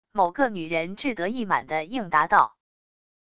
某个女人志得意满得应答到wav音频